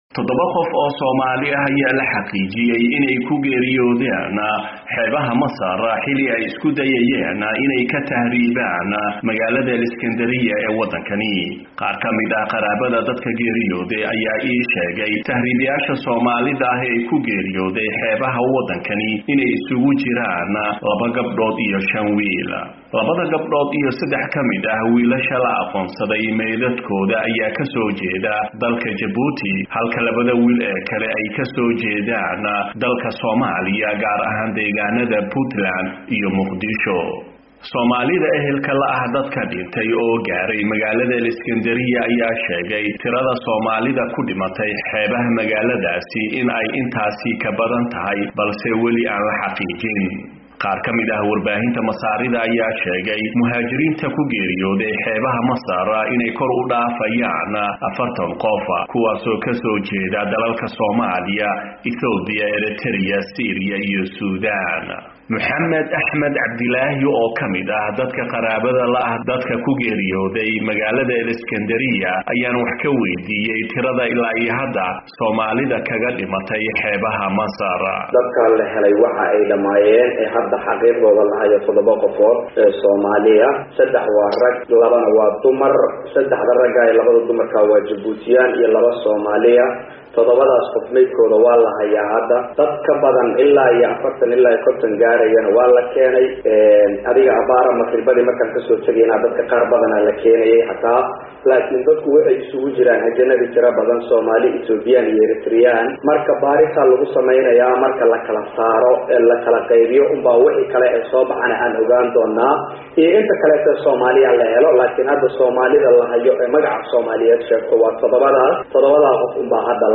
Warbixinta